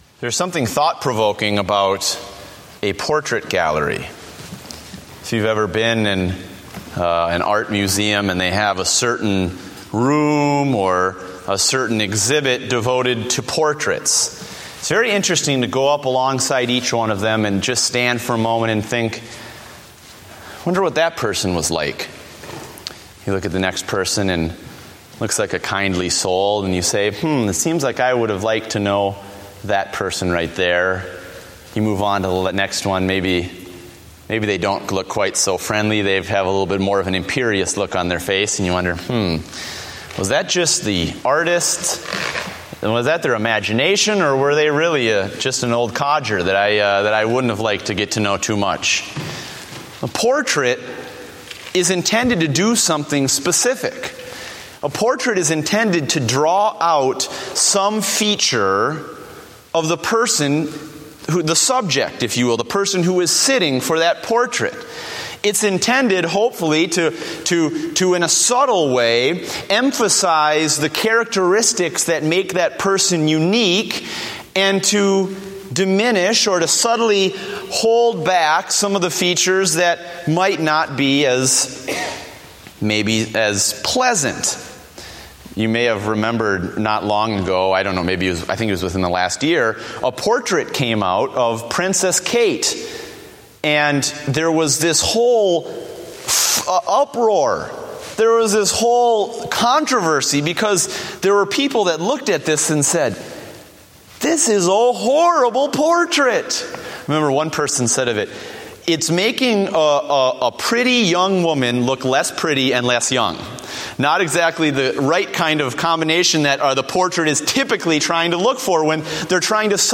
Date: June 15, 2014 (Evening Service)